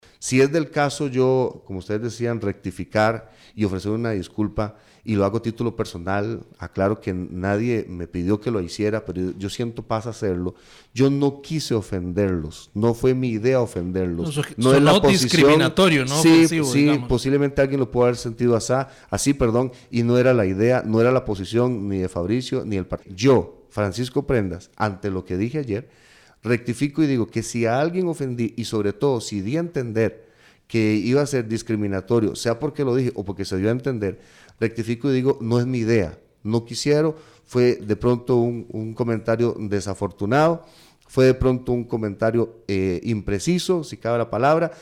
En el programa: La Lupa, Prendas expresó que sus declaraciones nunca fueron con la intención de discriminar a ningún grupo.